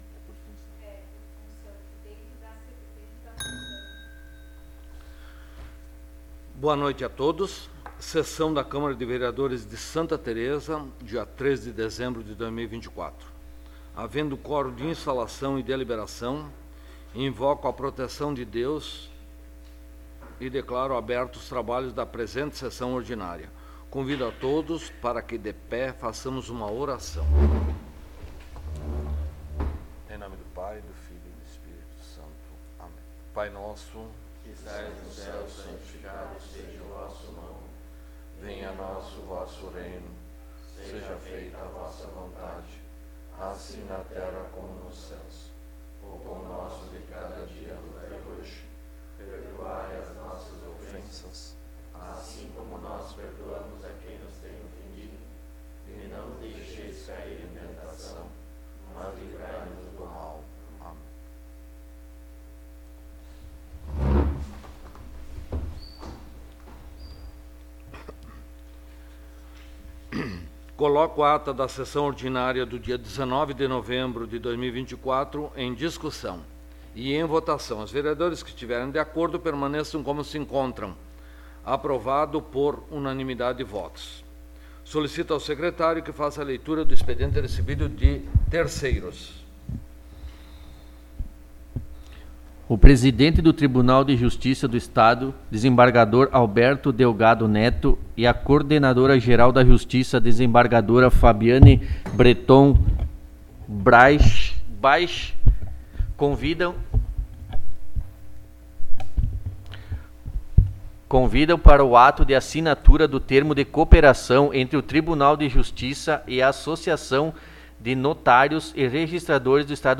Local: Câmara Municipal de Vereadores de Santa Tereza
Áudio da Sessão